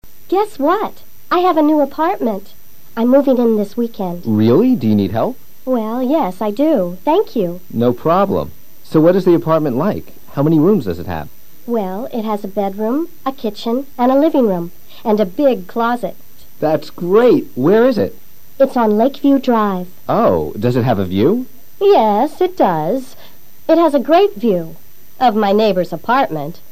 Linda le anuncia a Chris que se muda a un departamento nuevo. Escucha atentamente el diálogo y repítelo luego simultáneamente.